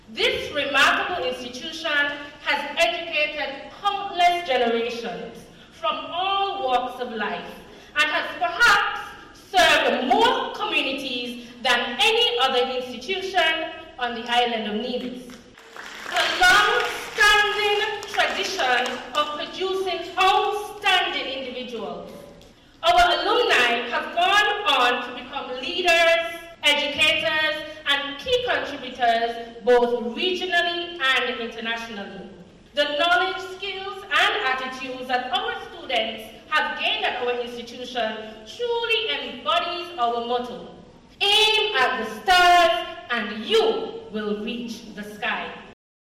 In marking 60 years since its inception, the Ivor Walters Primary School, held a thanksgiving service today, Monday September 15th, 2025, at the Nevis Performing Arts Centre (NEPAC).